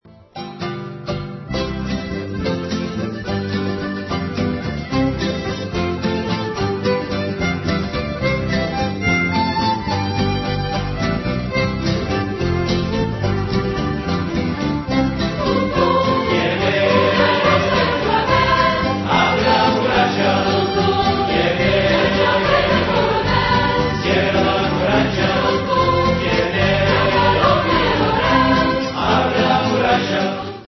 De mp3 zijn fragmenten in 'telefoon' kwaliteit (in het echt of op cd klinkt het natuurlijk veel beter :)